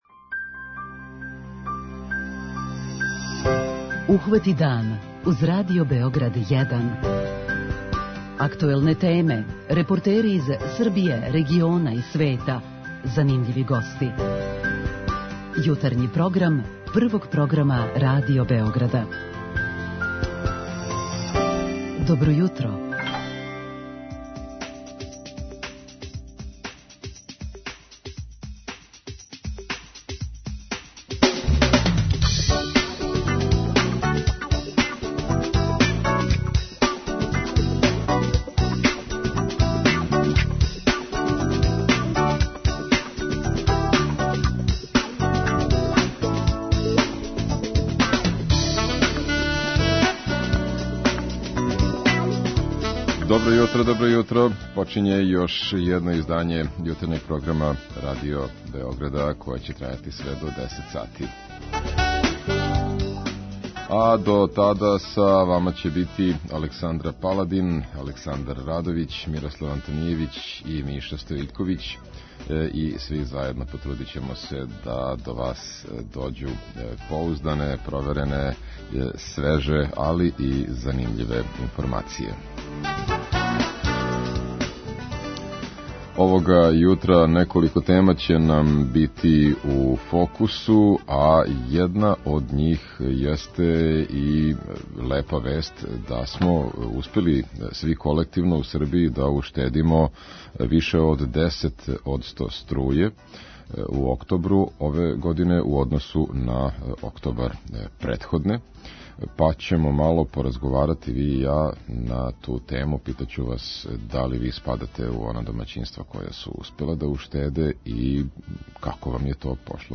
Како смо у томе успели - питаћемо наше слушаоце у редовној рубрици 'Питање јутра'.
Пробудите се уз јутарњи програм Радио Београда 1!